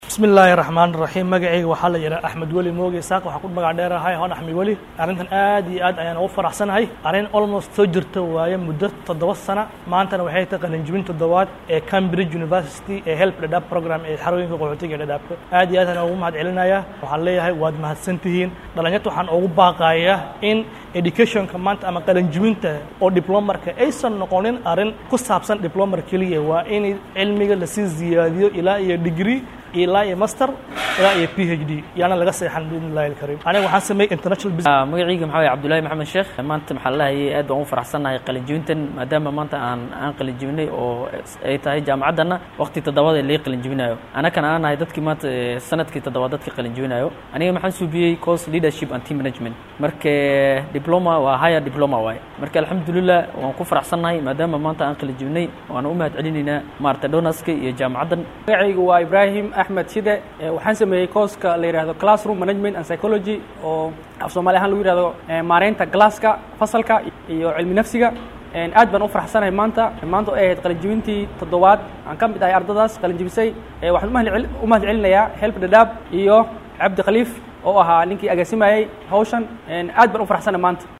Qaar ka mid ah ardayda qalin jabisay ayaa waarbahinta Star uga warramay dareenkooda.